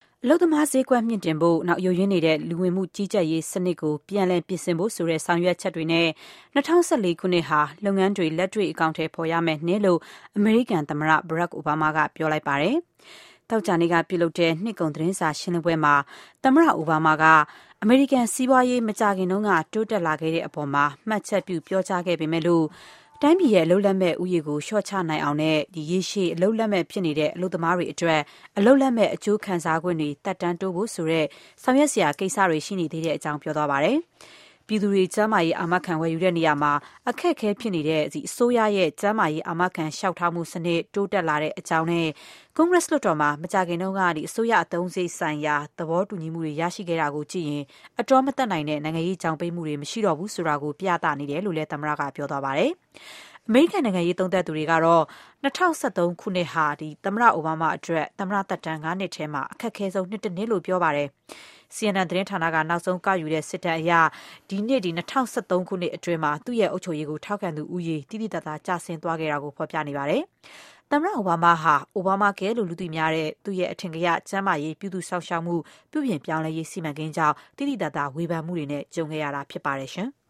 အိုဘားမား-နှစ်ကုန် မိန့်ခွန်း